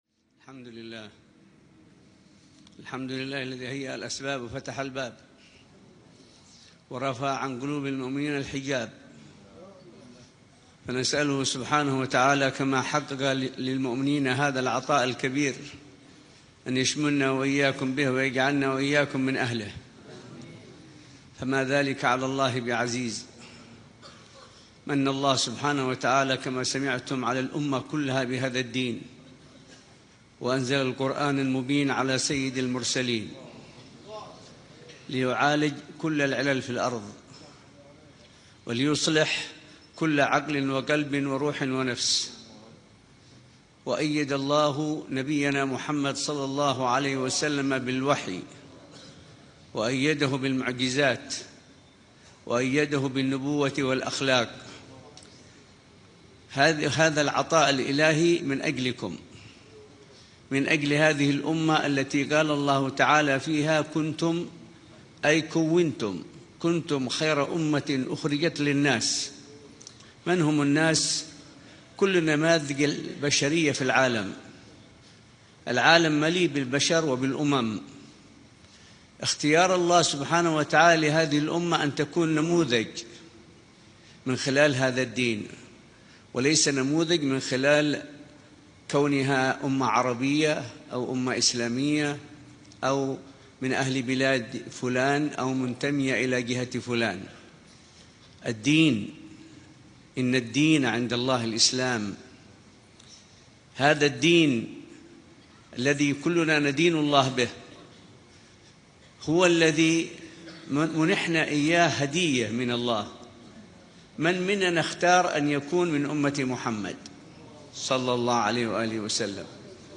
كلمة
بمجلس قراءة السيرة النبوية بدار المصطفى بتريم للدراسات الإسلامية